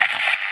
radio_on_light.ogg